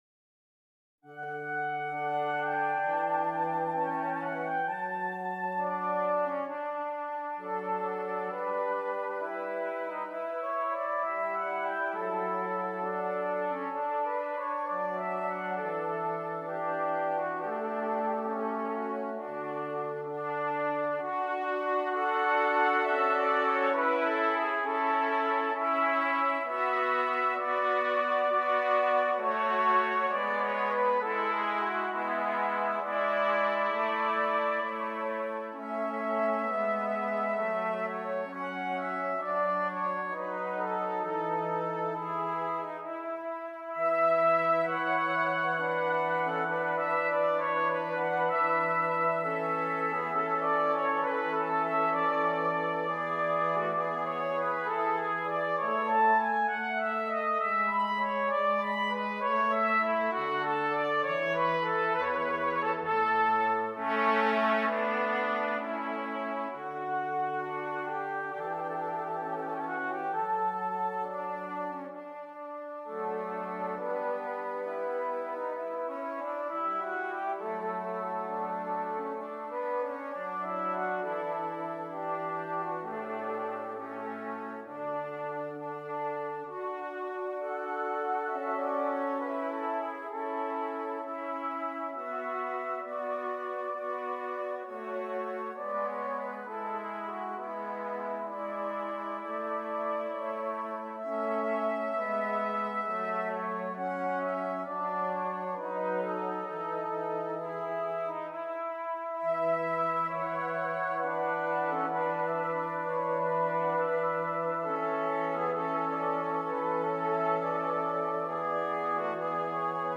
3 Trumpets and Bass Clef Instrument